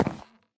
step / wood2